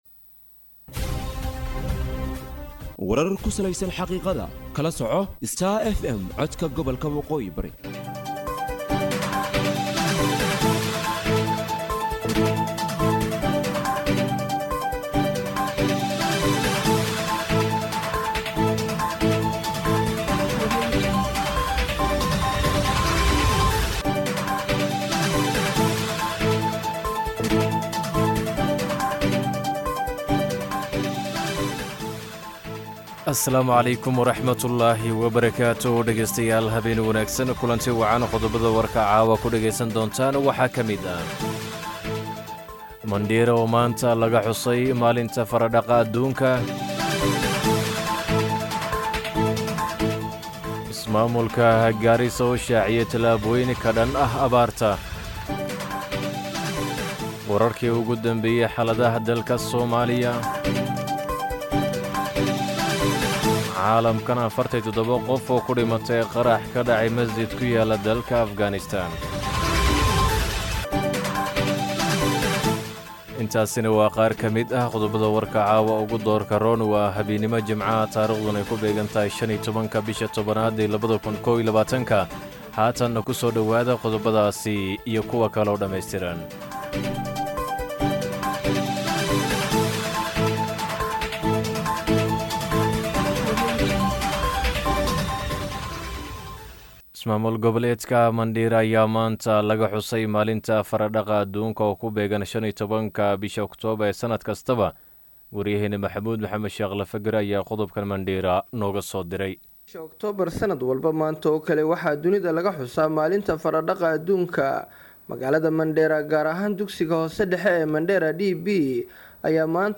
DHAGEYSO:WARKA HABEENIMO EE IDAACADDA STAR FM